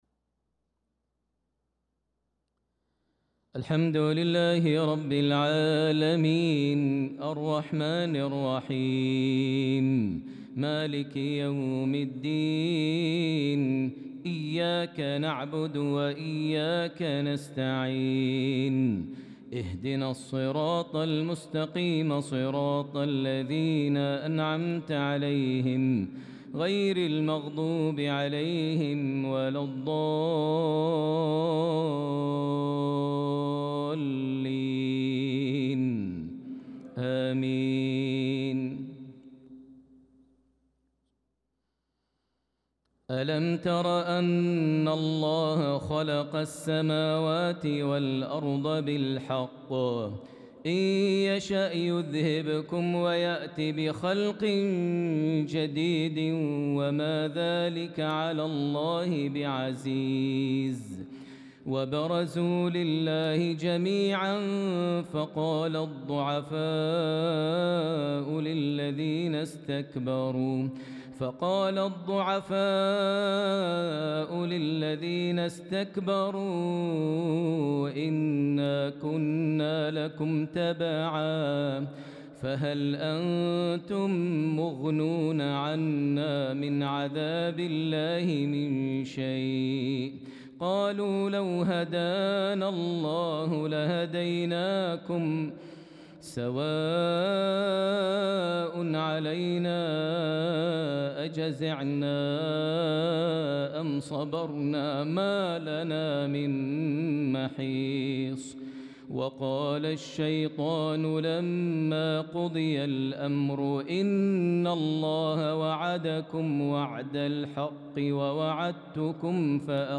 صلاة العشاء للقارئ ماهر المعيقلي 27 ذو الحجة 1445 هـ